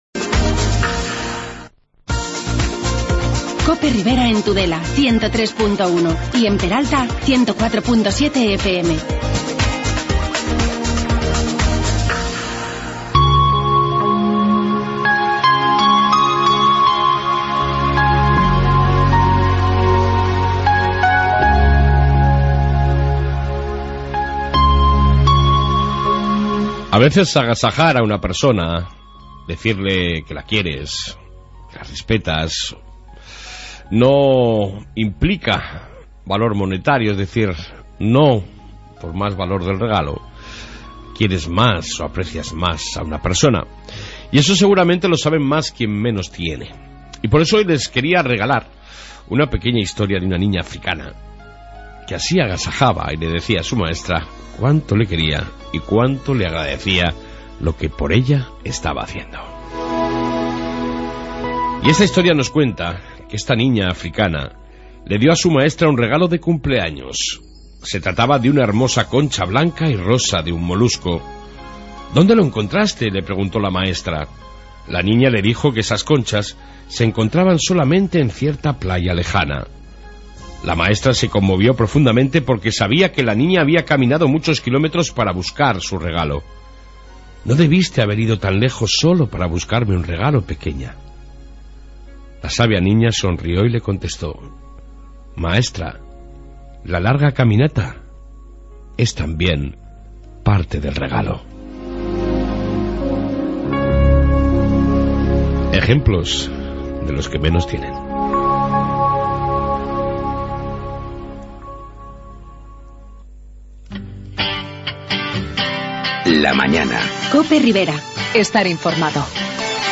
En esta 1 parte Reflexión Matutina, policía Municipal y entrevista con la concejal de Bienestar social, Mujer e Igualdad, Marisa Marqués, sobre asuntos de actualidad